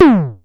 Vermona Perc 07.wav